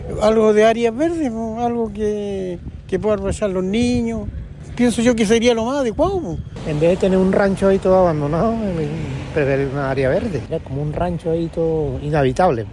Es más, en conversación con Radio Bío Bío, los residentes temen que nuevamente se tomen los terrenos ubicados en calle Roma, por lo que esperan que el lugar se convierta en áreas verdes o en un sector de juegos para los niños.